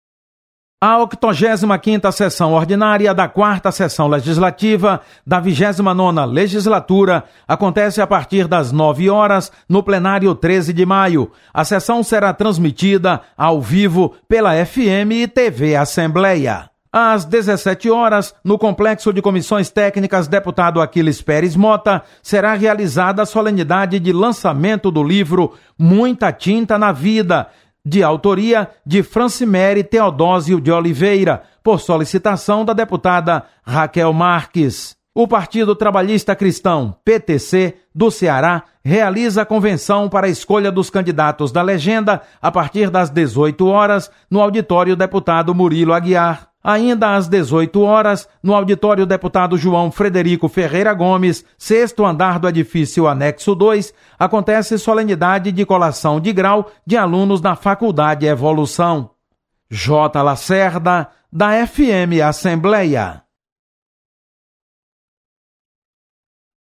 Acompanhe as atividades de hoje da Assembleia Legislativa. Repórter